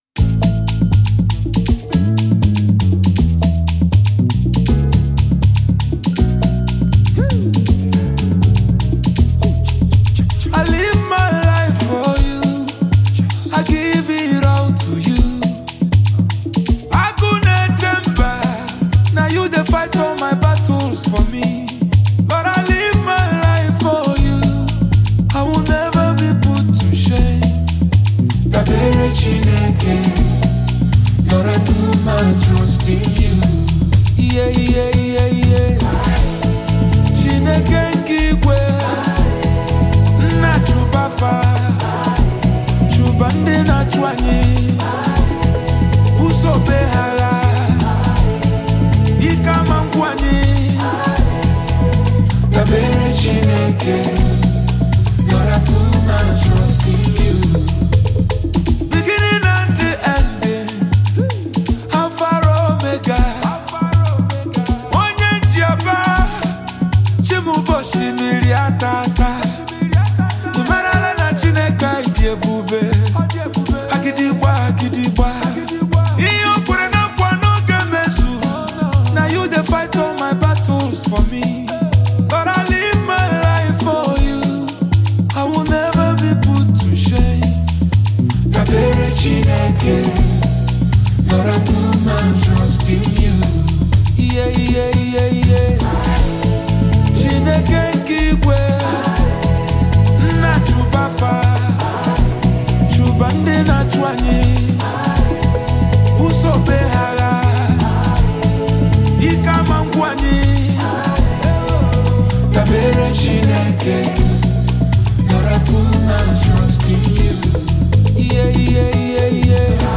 soul-stirring track